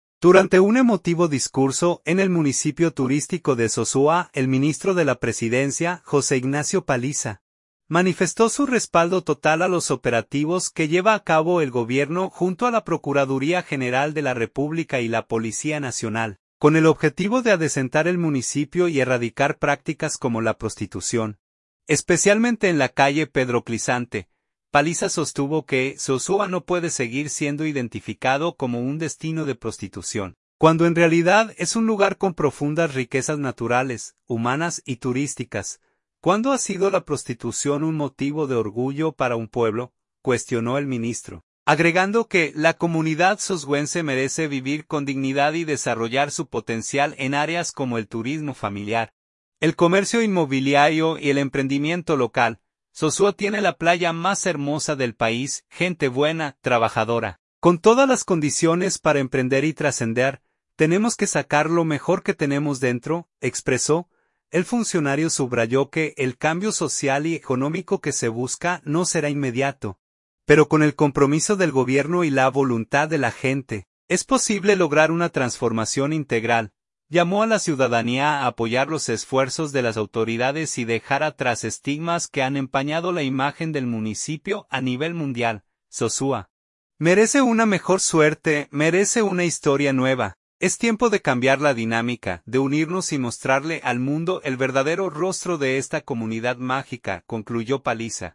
Sosúa, Puerto Plata. – Durante un emotivo discurso en el municipio turístico de Sosúa, el ministro de la Presidencia, José Ignacio Paliza, manifestó su respaldo total a los operativos que lleva a cabo el Gobierno junto a la Procuraduría General de la República y la Policía Nacional, con el objetivo de adecentar el municipio y erradicar prácticas como la prostitución, especialmente en la calle Pedro Clisante.